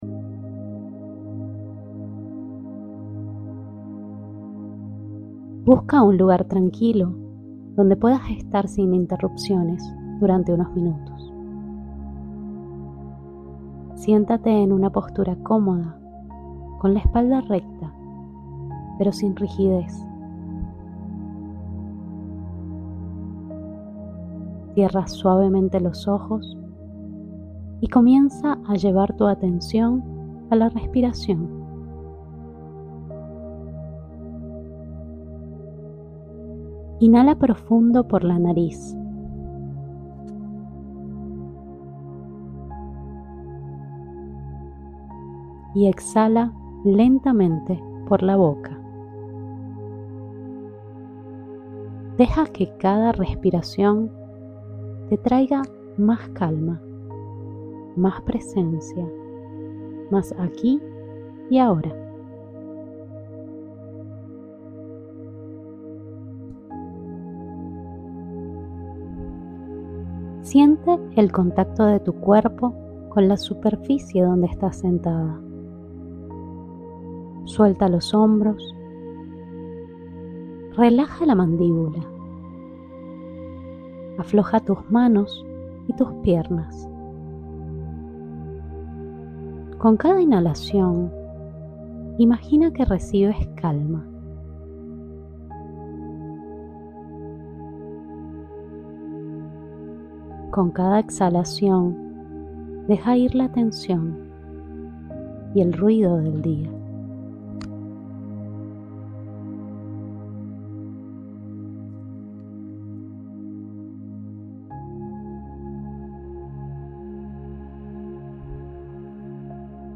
Audio - Meditación guiada "Reconecta con tu autenticidad"